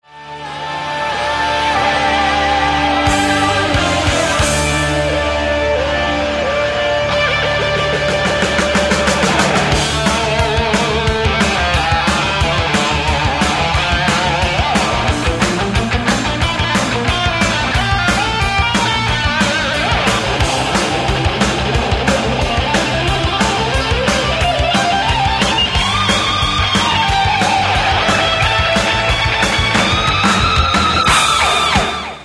SOLO